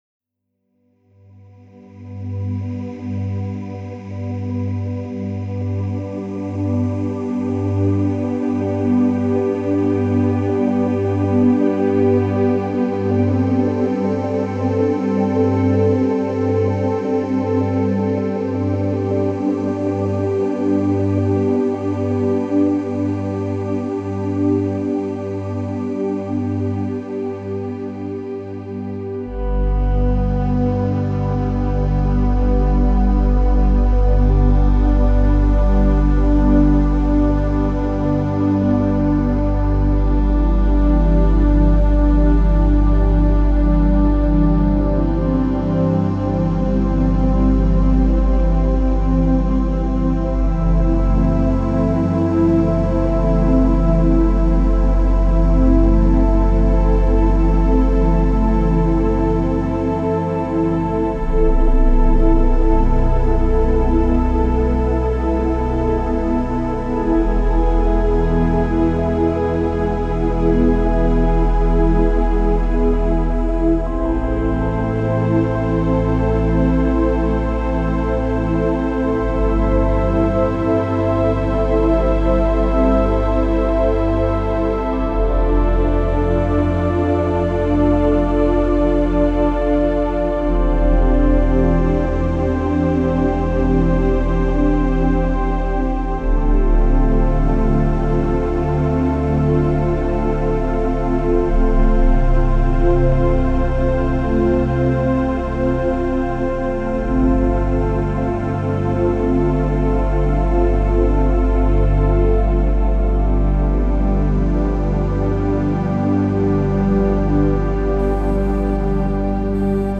Calming music for stress relief, pre-sleep, and meditation.